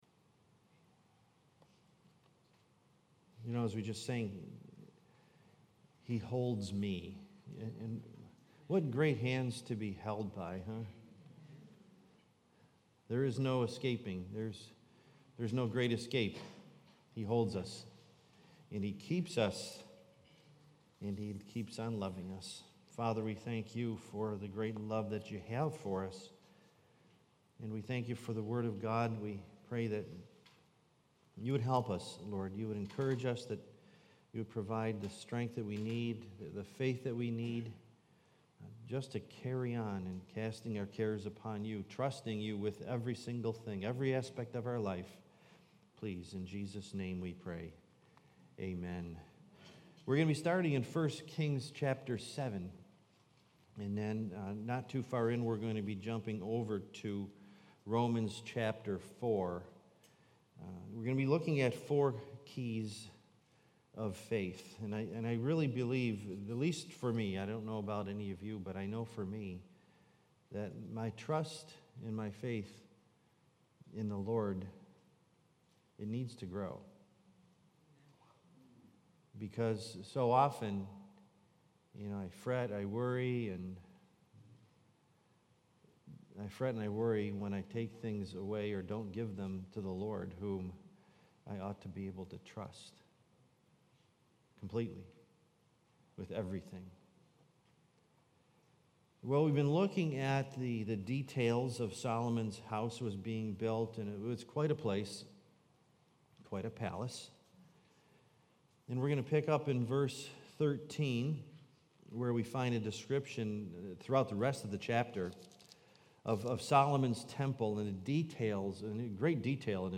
Series: Wednesday Bible Study